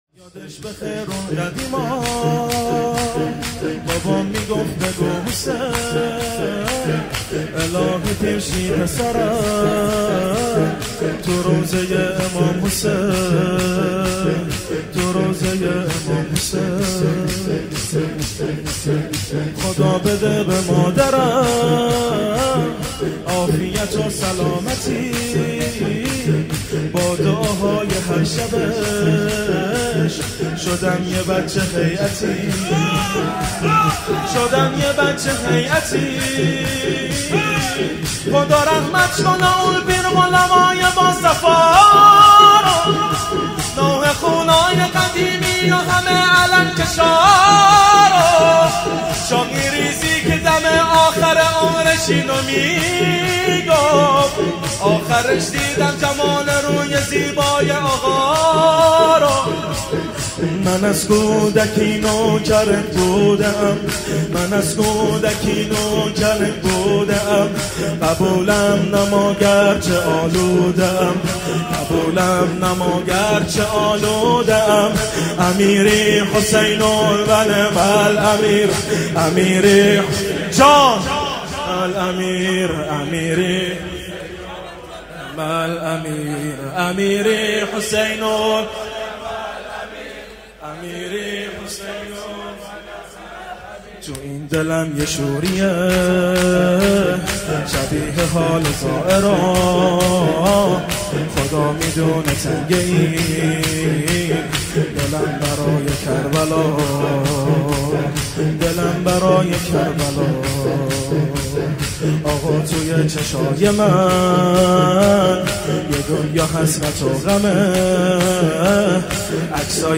شور
مداحی
شب 1 محرم سال 1439 هجری قمری | هیأت علی اکبر بحرین